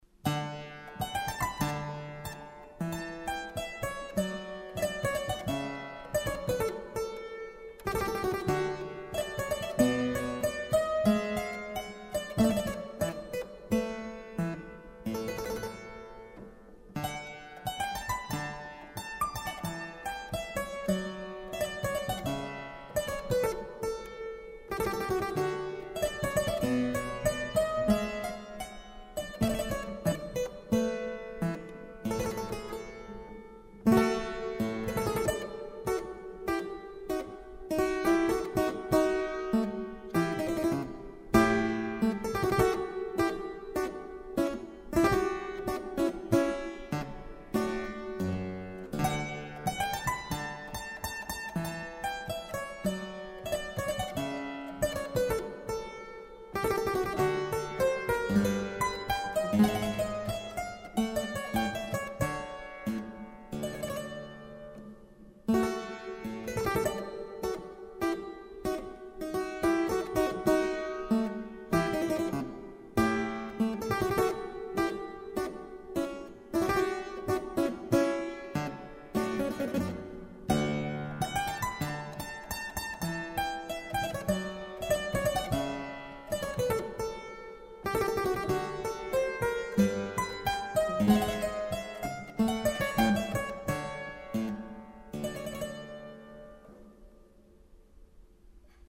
Klavier- (eigentlich: Klavichord-) Stücke